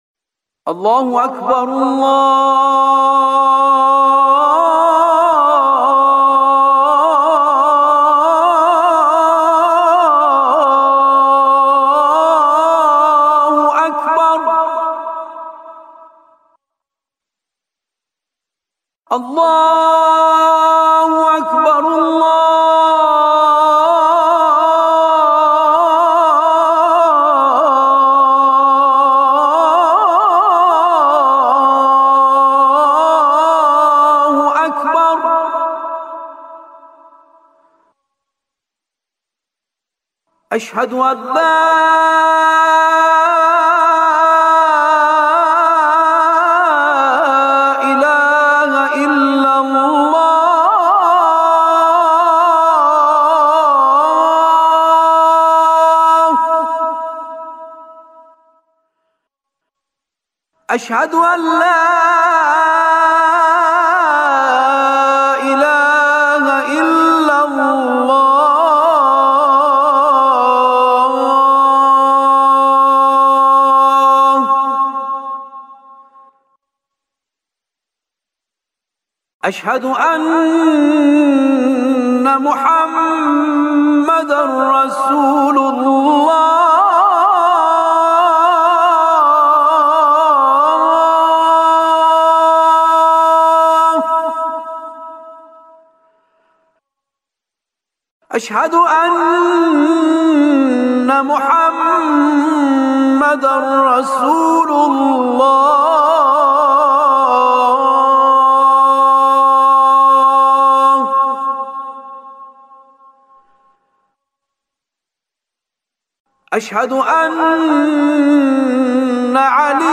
سال‌ها ممارست برای ارائه اذانی ماندگار / اجرای 2 اذان در مقام‌های «رست» و «سه‌گاه»
اذان